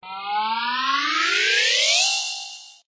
object_grow_1.ogg